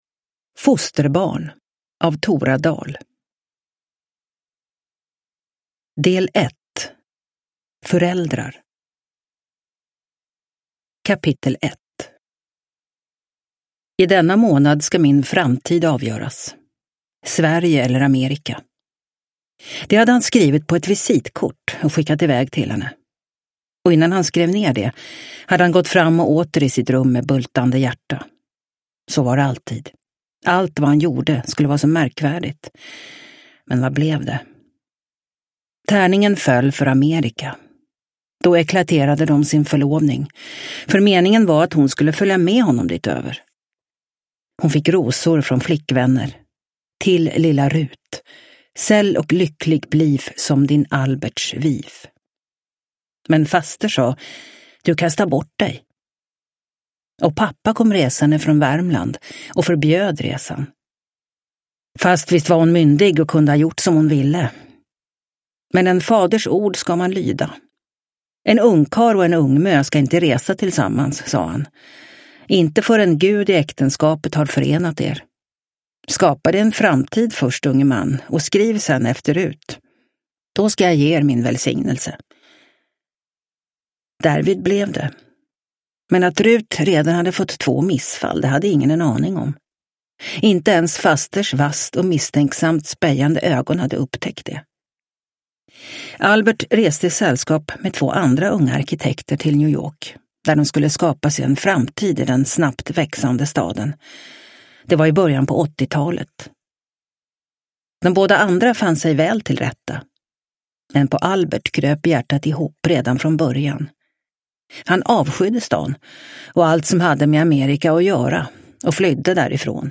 Fosterbarn – Ljudbok – Laddas ner